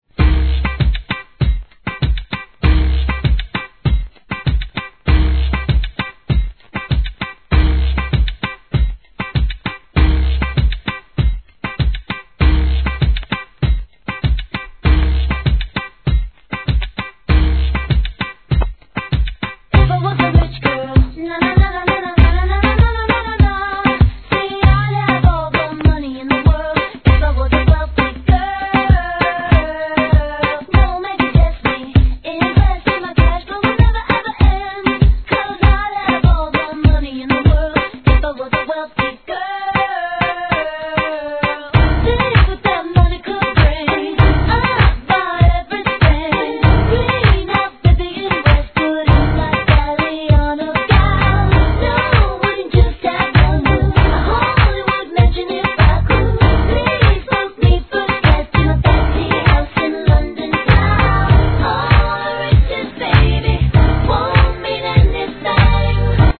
HIP HOP/R&B
人気曲のDJユースなエディ ット・トラック集!!